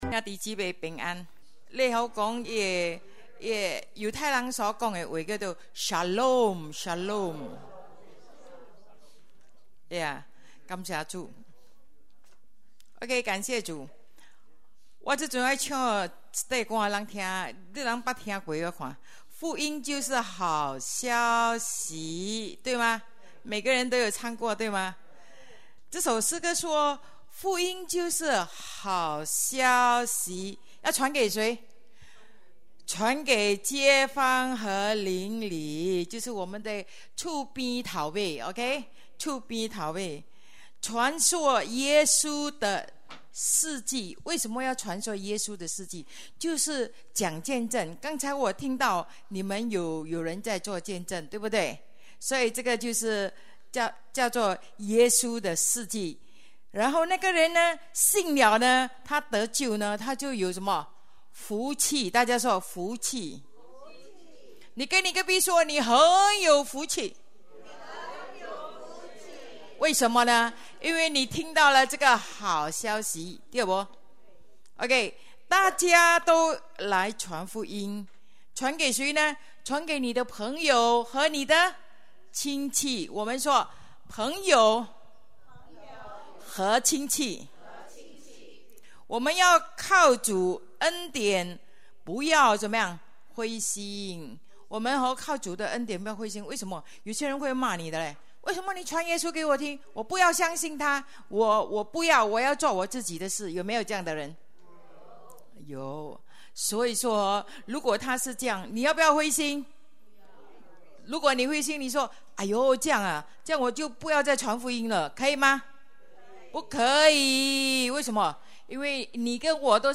Sermon | GMB